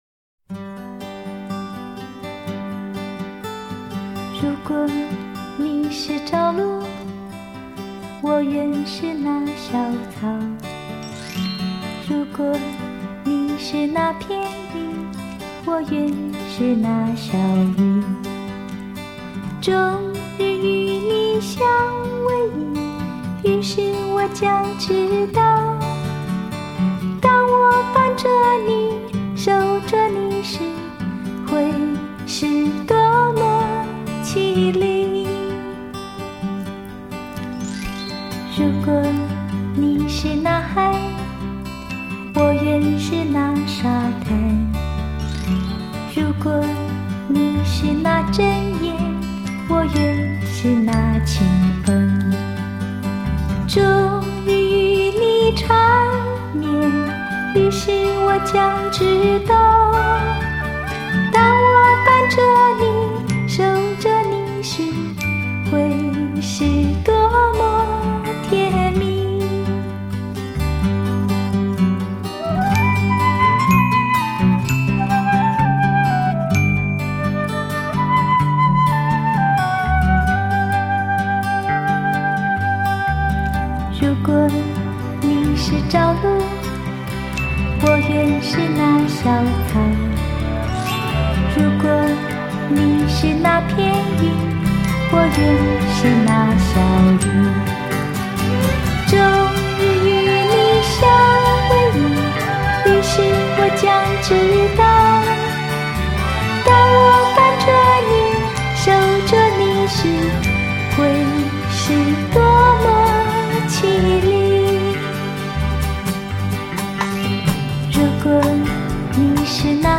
正宗新格原版 绝对原声原唱